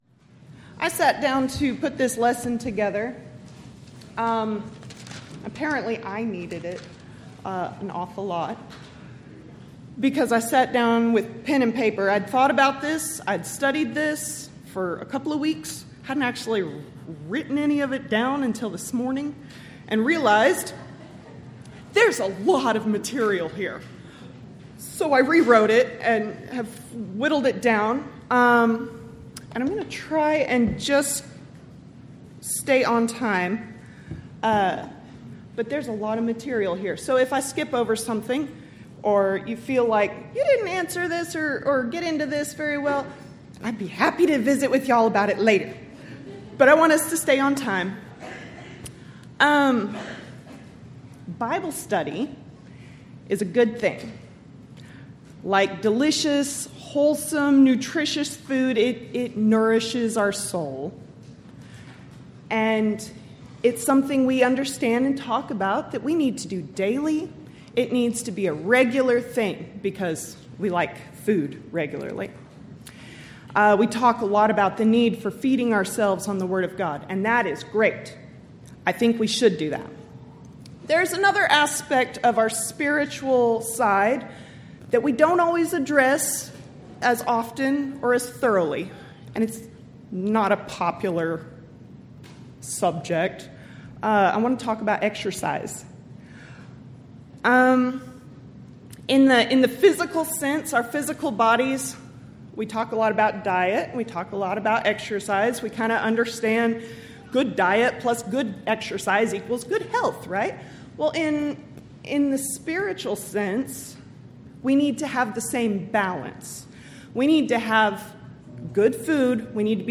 Title: Thursday PM Devotional
Event: 13th Annual Texas Ladies in Christ Retreat Theme/Title: Studies in 1 Corinthians